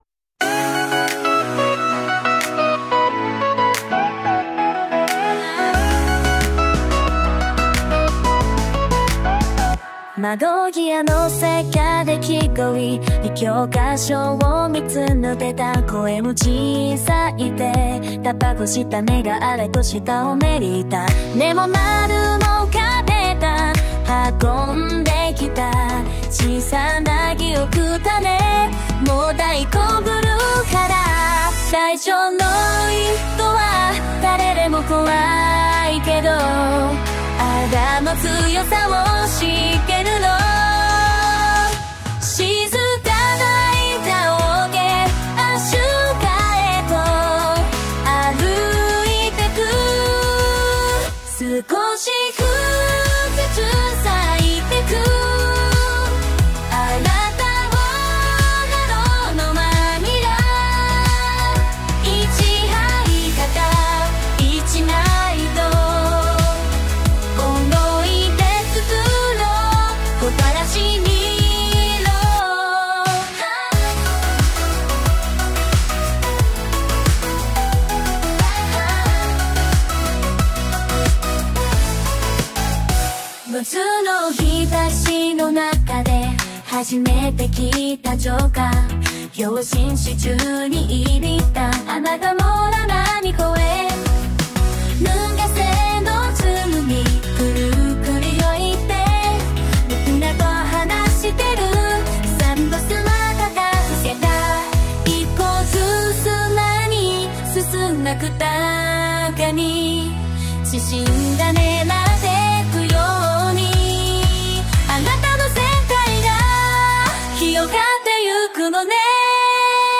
女性ボーカルの楽曲も生成しました。